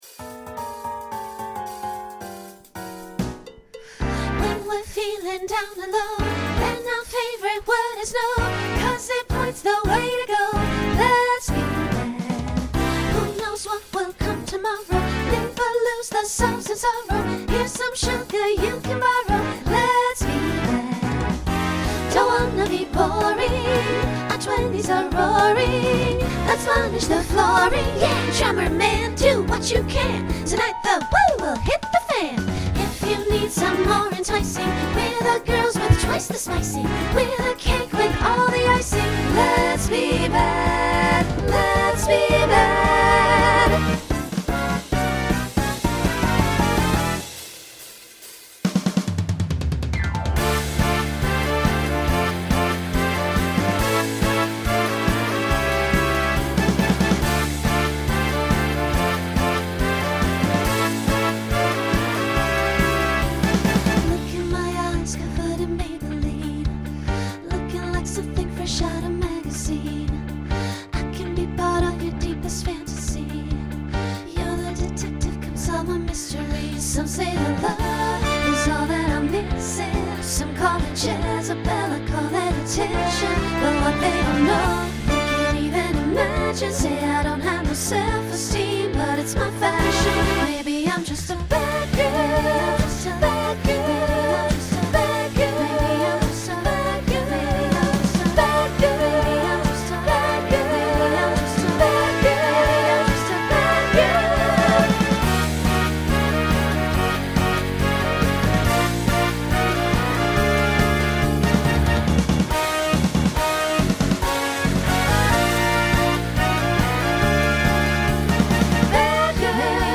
Broadway/Film , Pop/Dance Instrumental combo
Voicing SSA